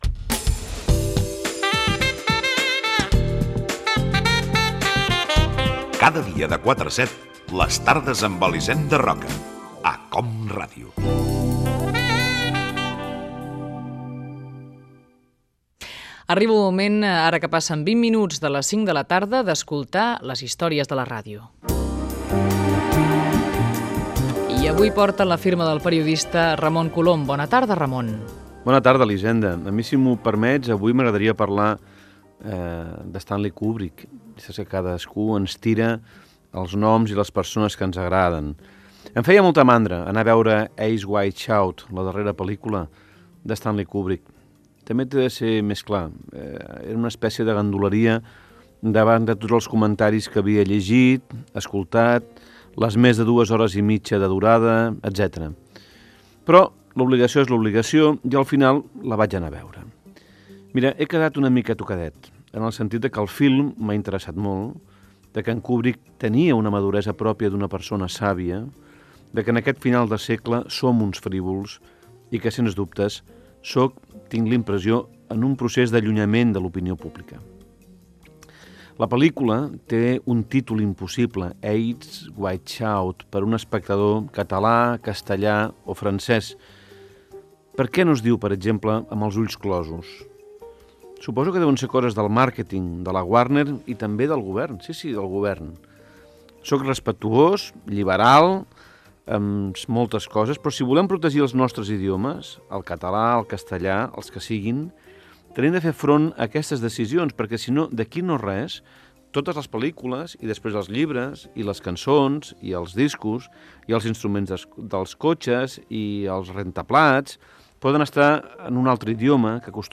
Indicatiu del programa, hora, secció "Històries de la ràdio" amb el periodista Ramon Colom. Dedicada a la pel·lícula "Eyes Wide Shut" del director Stanley Kubrick
Entreteniment